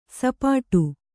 ♪ sapāṭu